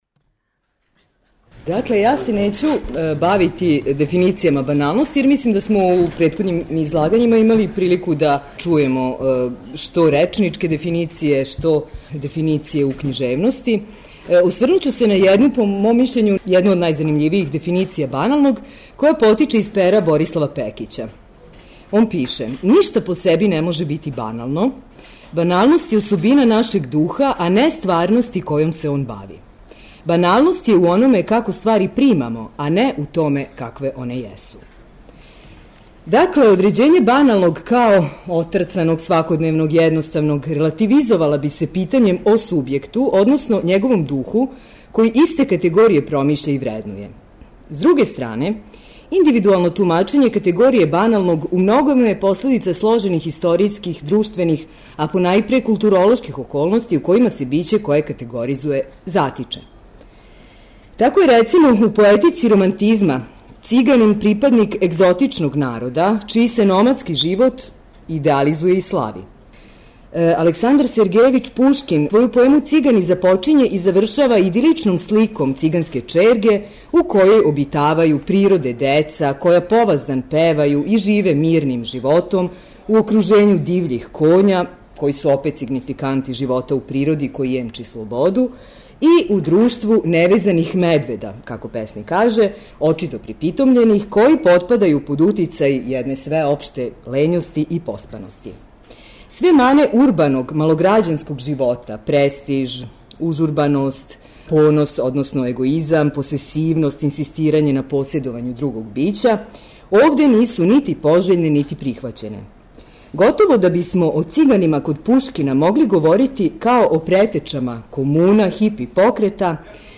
са округлог стола посвећеног теми 'Поезија и баналност', који је одржан 31. августа у Библиотеци града Новог Сада, а у оквиру једанаестог Међународног новосадског књижевног фестивала.